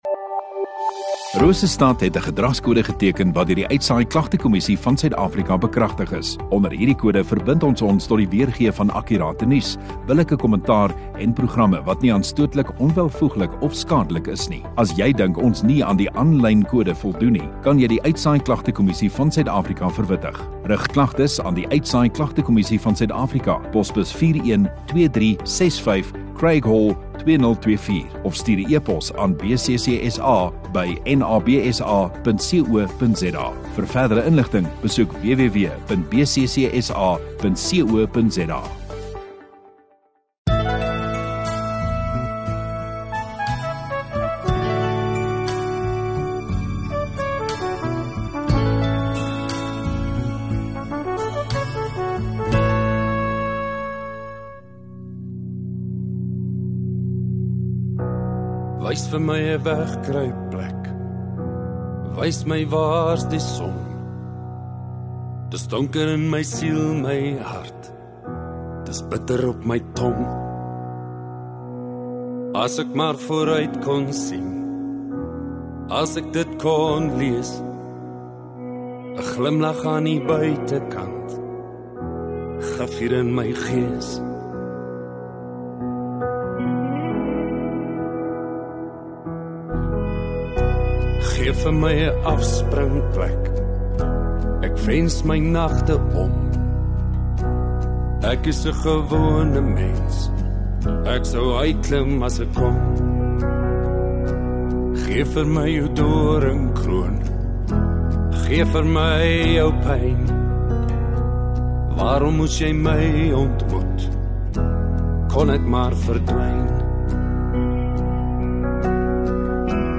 8 Oct Sondagoggend Erediens